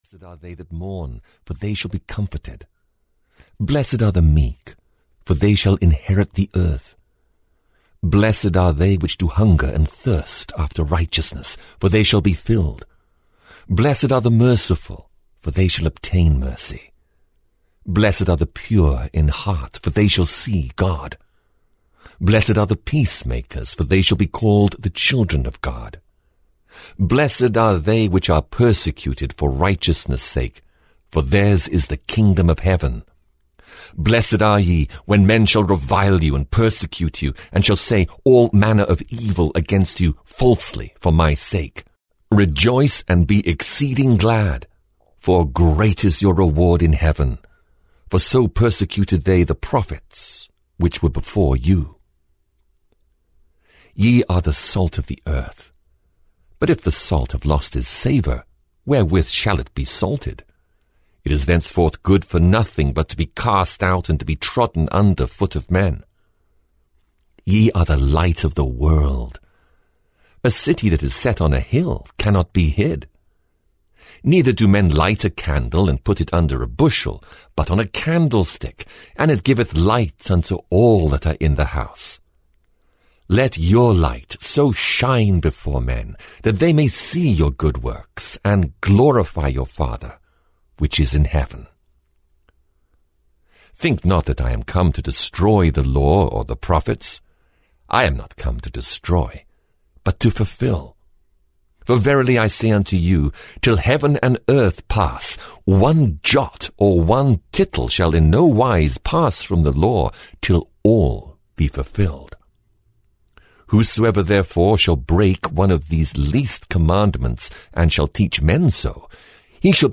The New Testament 1 - Matthew (EN) audiokniha
Ukázka z knihy